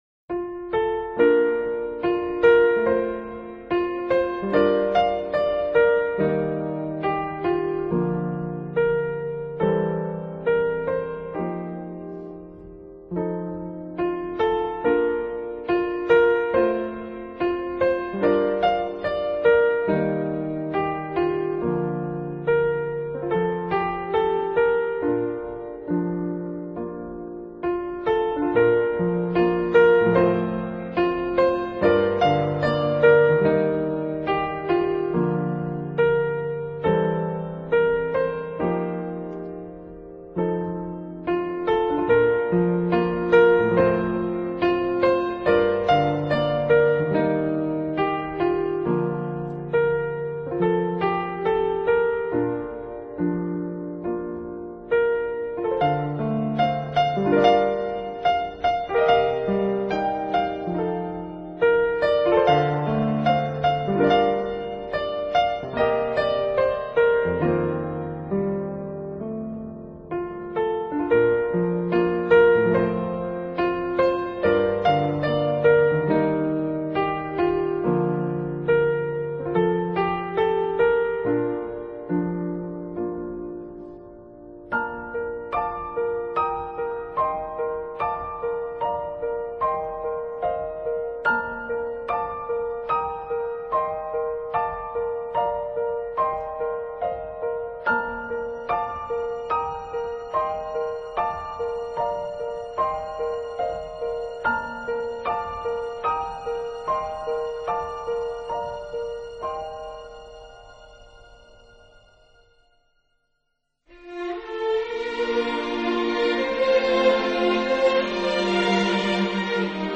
音乐类型：NewAge 新世纪
音乐风格：普通新世纪